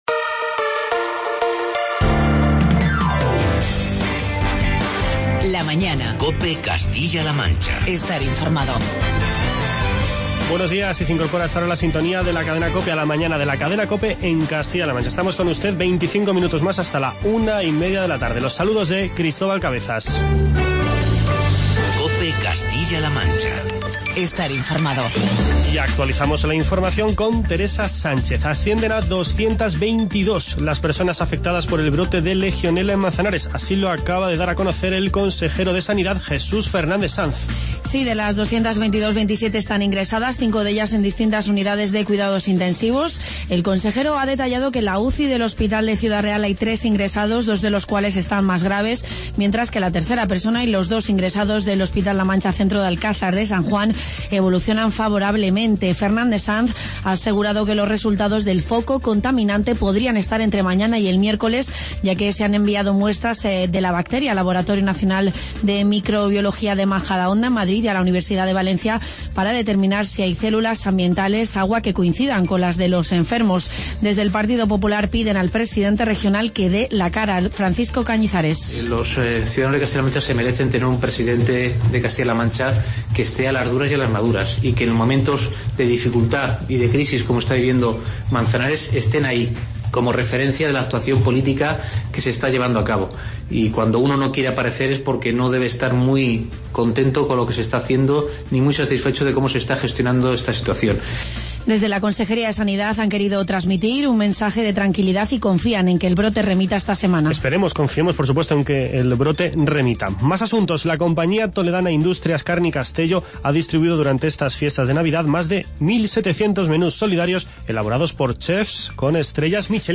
Continuamos el programa conversando con invitados